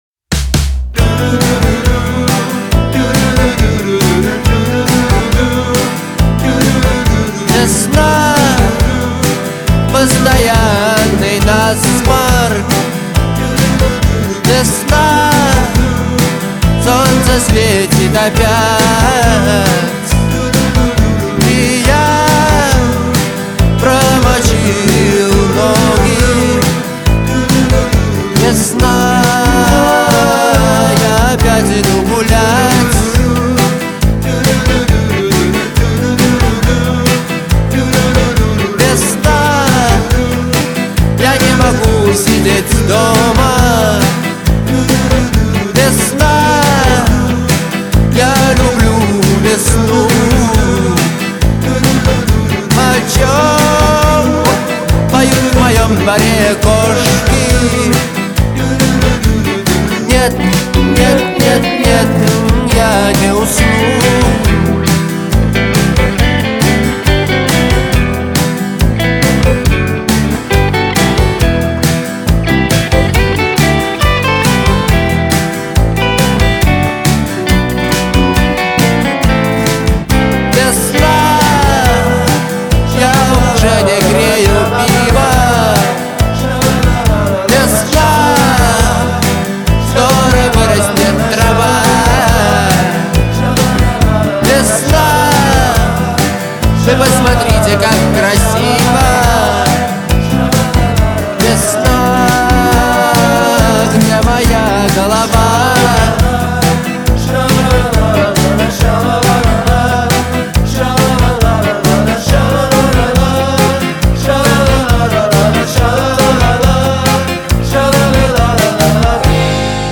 Жанр: Рок